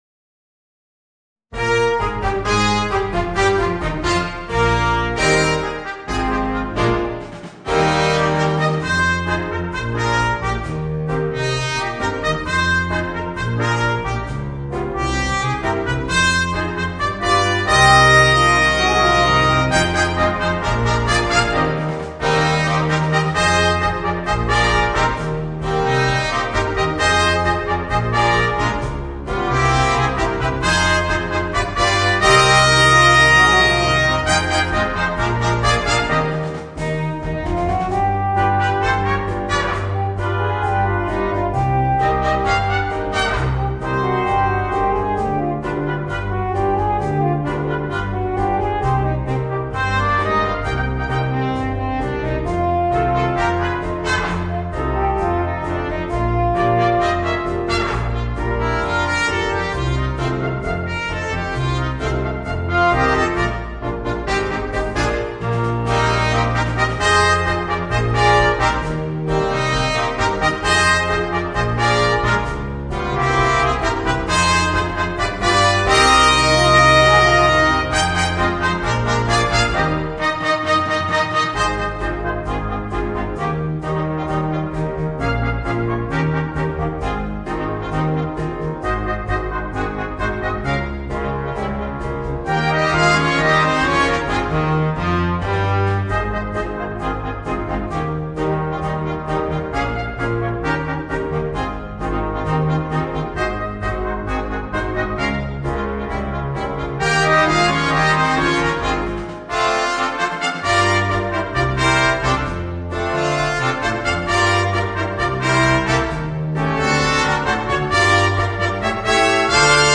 Voicing: 2 Trumpets, 3 Trombones and Drums